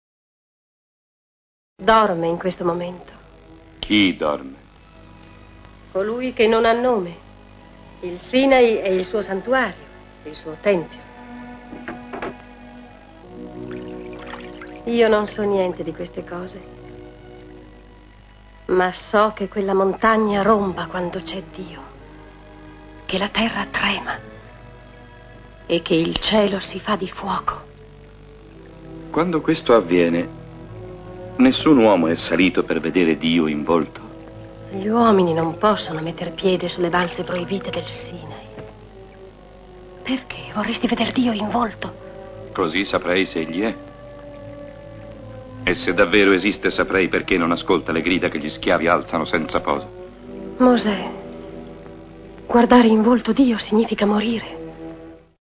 Dopo aver interpretato ruoli secondari in teatro e al cinema, si è dedicata interamente al doppiaggio, dove è spesso riconoscibile grazie alla sua caratteristica "s" romagnola.